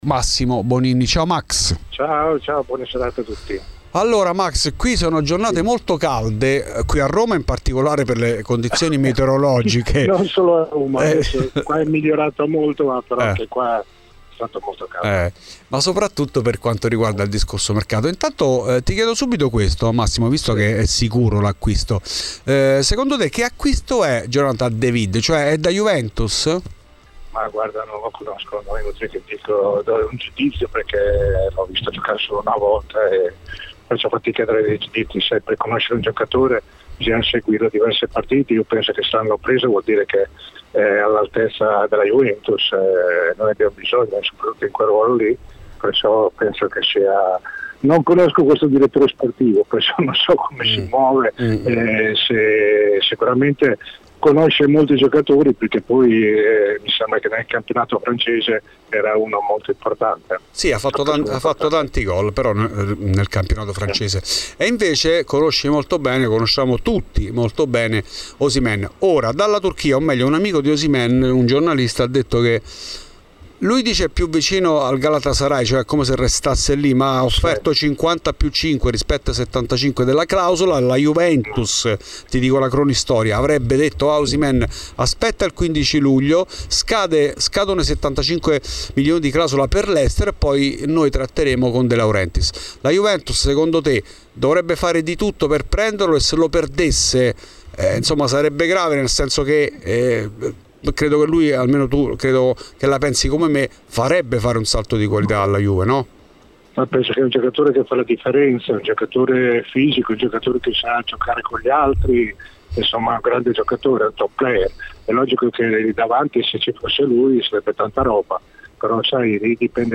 Podcast Bonini a RBN: "Juve prendi Osimhen.
In ESCLUSIVA a Fuori di Juve Massimo Bonini.